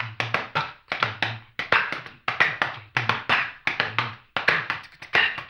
HAMBONE 06-L.wav